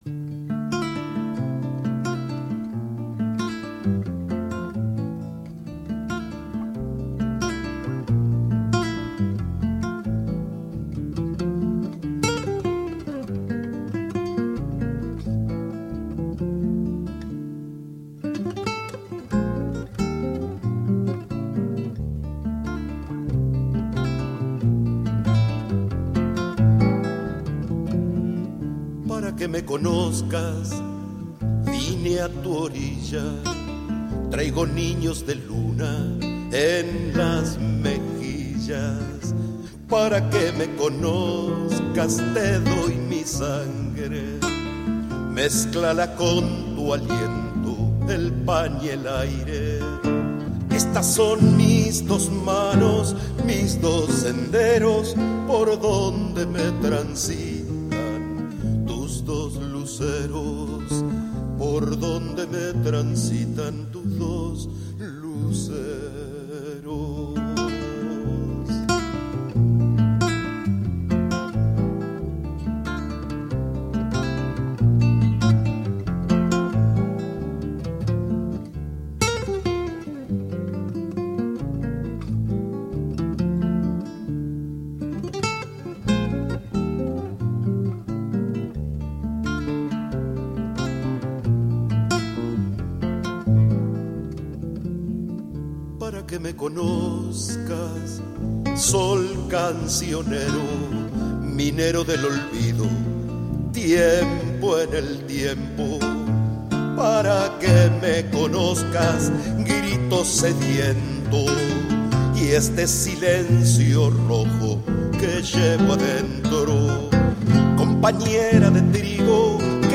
huella